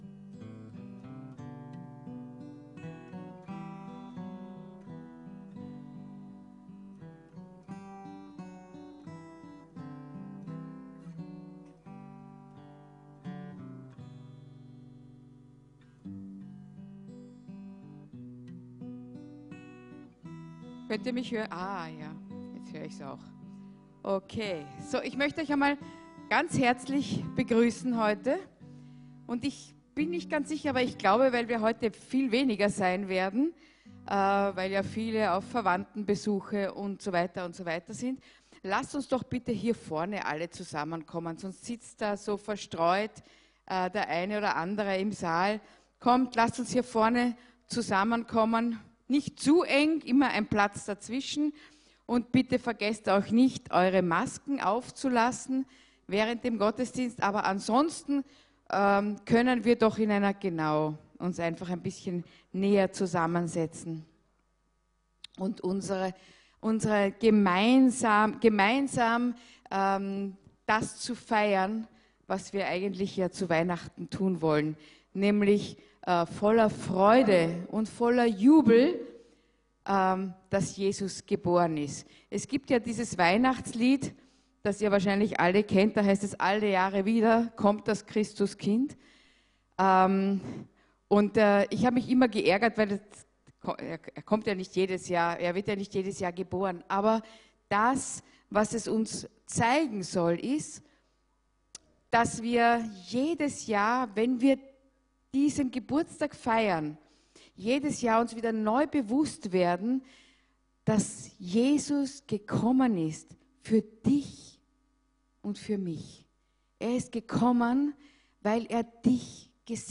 Weihnachtsgottesdienst